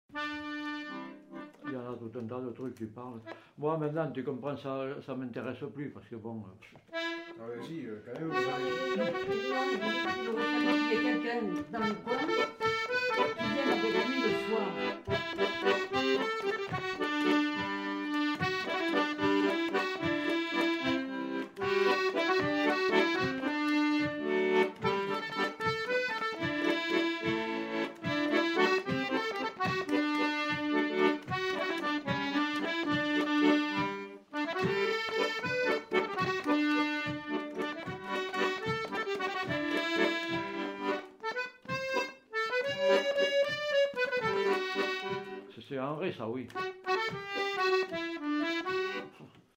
répertoire de danses joué à l'accordéon chromatique
enquêtes sonores
Tango